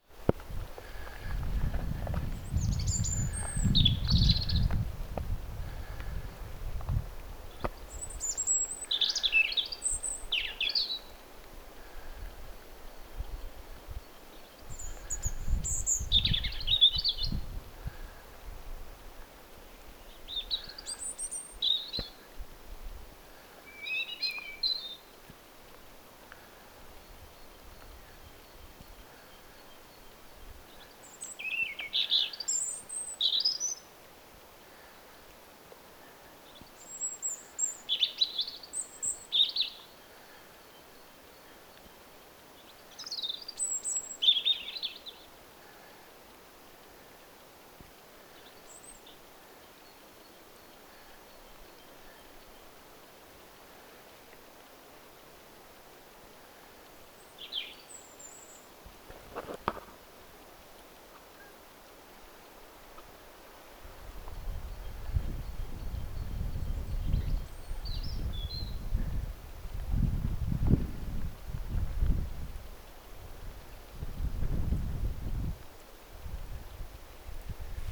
Tässä on neljä tai viisi laulavaa punarintaa.
3s_punarinta_laulaa.mp3